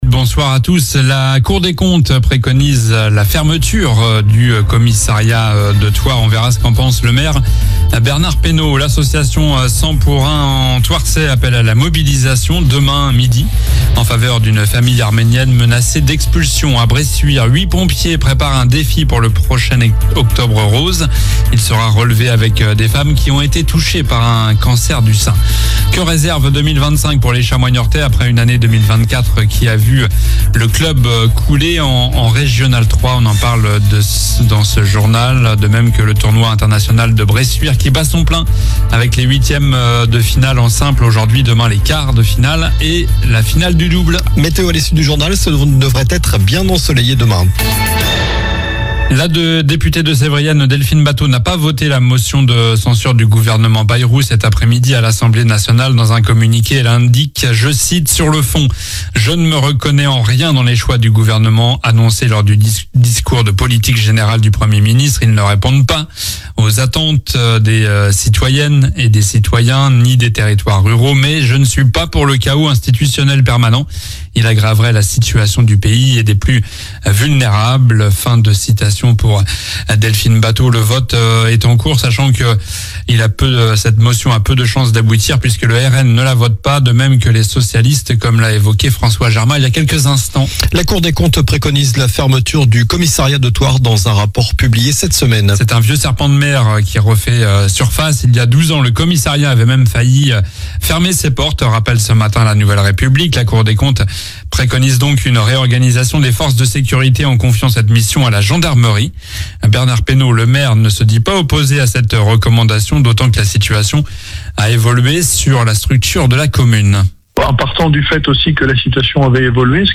Journal du jeudi 16 janvier (soir)